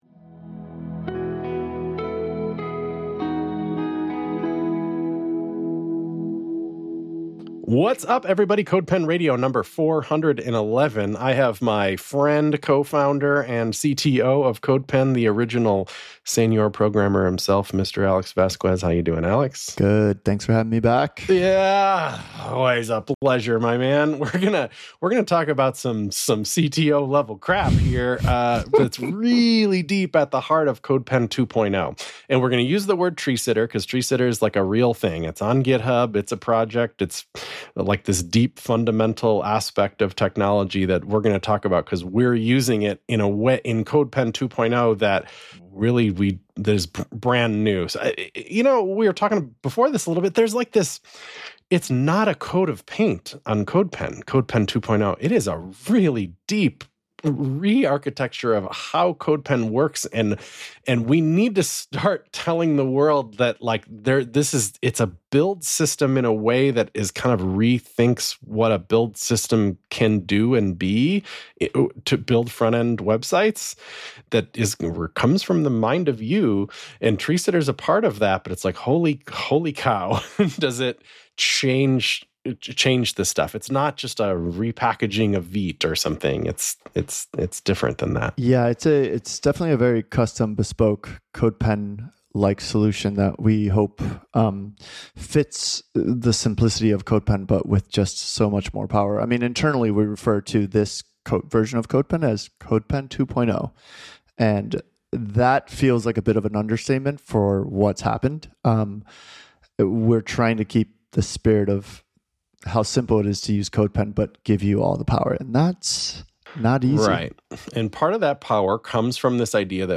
The CodePen team talk about the ins and outs of running a web software business.